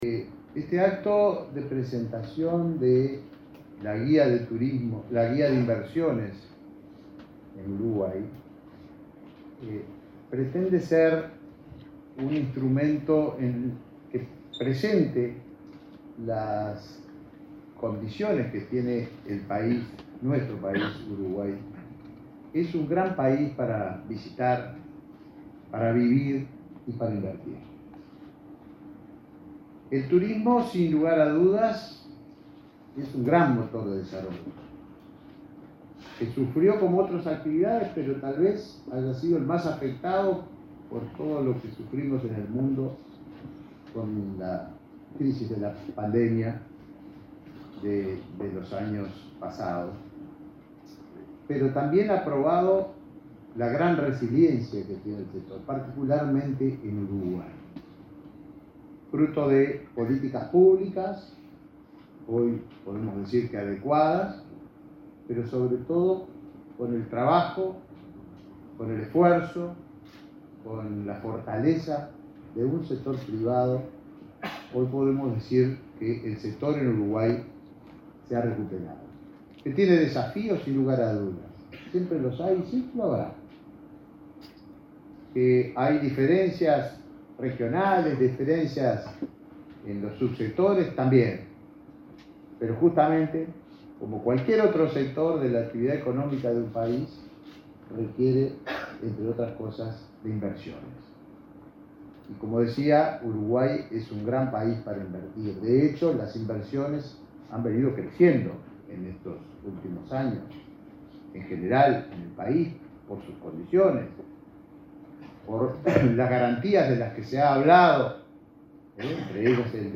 Palabras del ministro de Turismo, Tabaré Viera
El titular del Ministerio de Turismo, Tabaré Viera, se expresó en el lanzamiento de la primera guía de inversiones elaborada por esa cartera,